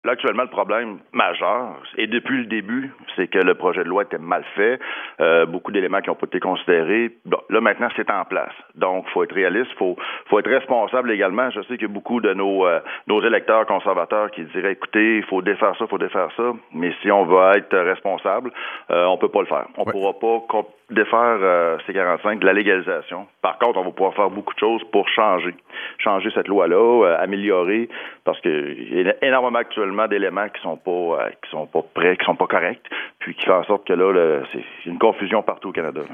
Por otra parte, el diputado conservador Pierre Paul-Hus, en entrevista con la radio francesa de Radio Canadá, decía este lunes por la mañana que hay que ser realista y aceptar que no es posible criminalizar nuevamente el consumo de marihuana en el país.